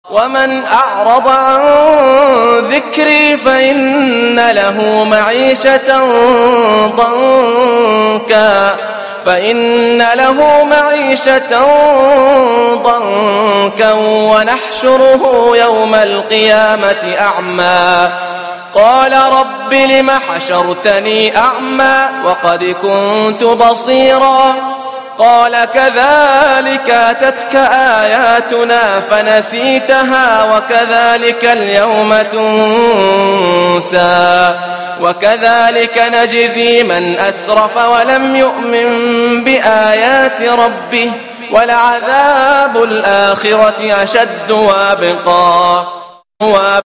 صوت جميل على ترتيل خاشع ومنيب